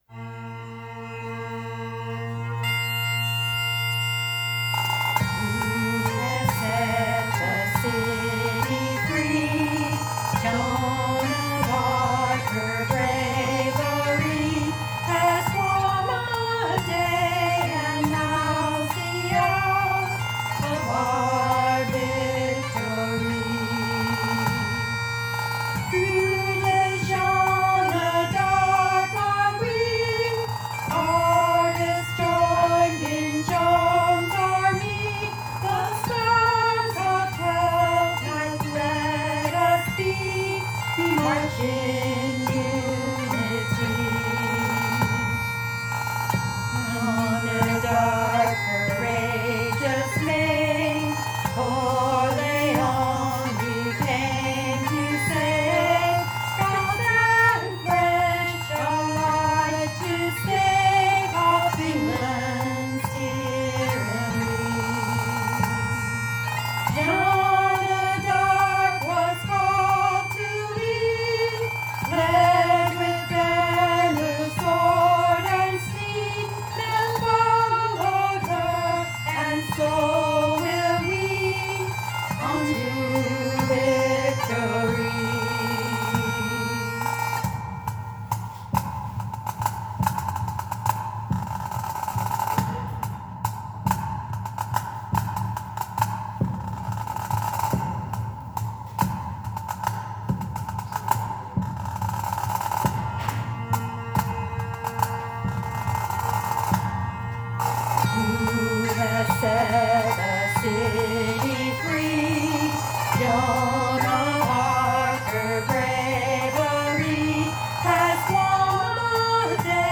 Krewe de Jeanne d’Arc lyrics to the traditional tune Scots Wha Hae / Hey Tuttie Tatie
• According to historical records, this ancient Scottish battle tune was played in 1429 by the pipers accompanying Joan of Arc, her French army and their Scottish allies, into battle to raise the English siege of Orléans, France.
practice track mp3
Note:  the video below is strictly a rehearsal track, made informally on a phone at a krewe workshop so people can see how the song goes and practice it yourself.